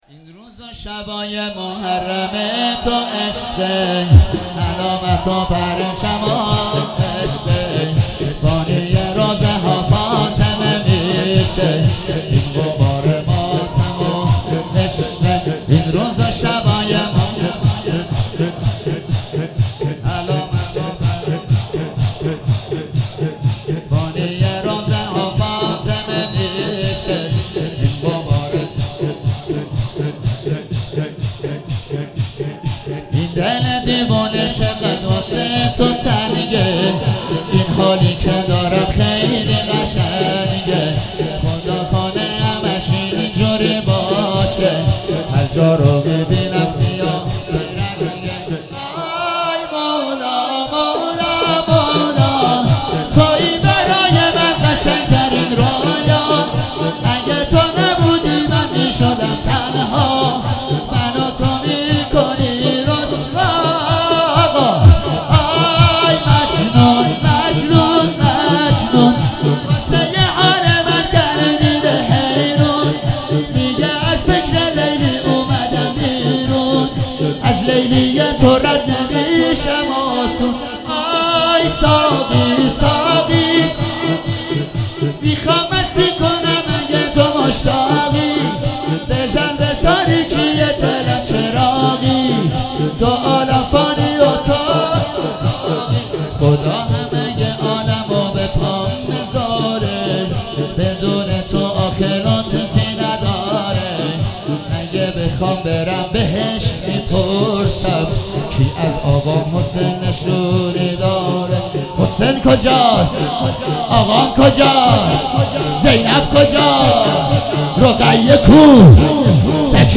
شب اول محرم - به نام نامی حضرت مسلم(ع)